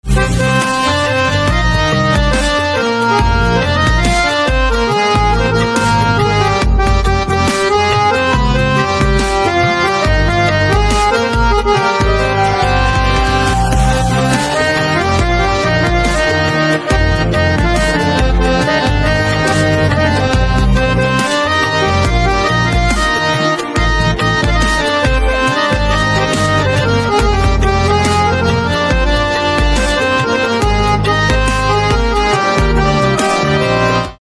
4. Unqiue Piano Instrumental Ringtone.
Piano melodies create elegant and emotional ringtones.